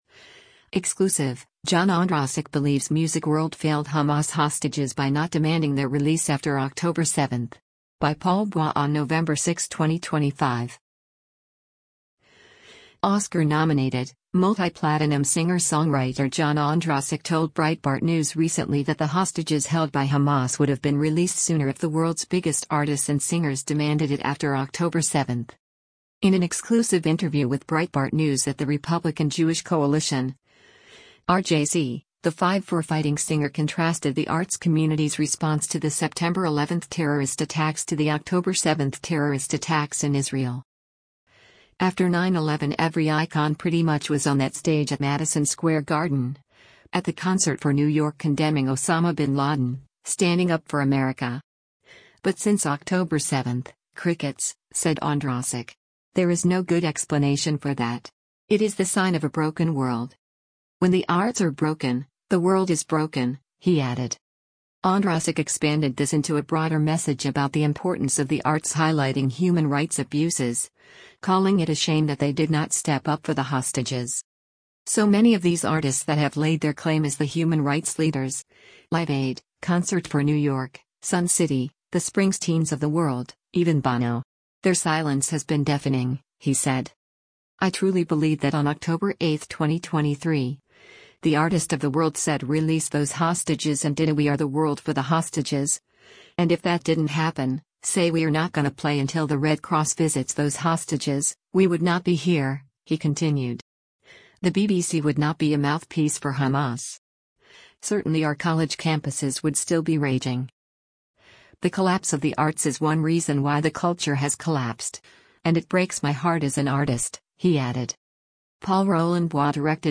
In an exclusive interview with Breitbart News at the Republican Jewish Coalition (RJC), the “Five for Fighting” singer contrasted the arts community’s response to the September 11 terrorist attacks to the October 7 terrorist attacks in Israel.